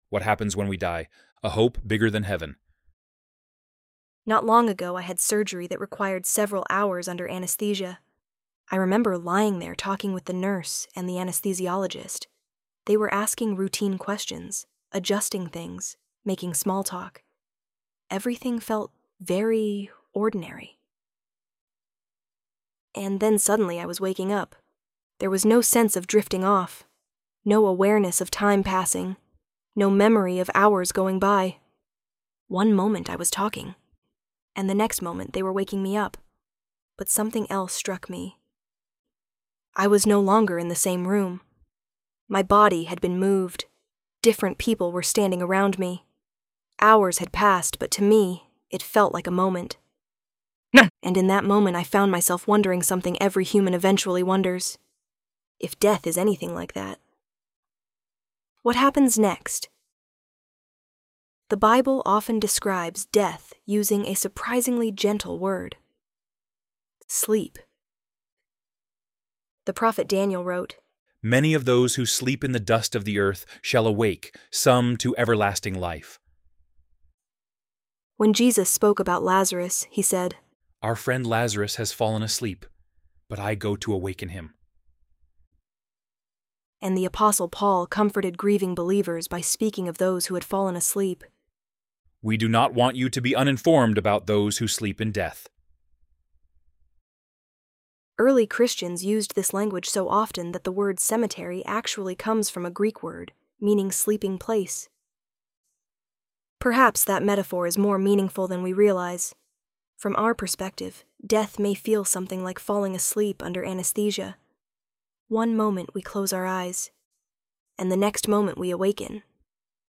ElevenLabs_What_Happens_When_We_Die_2.mp3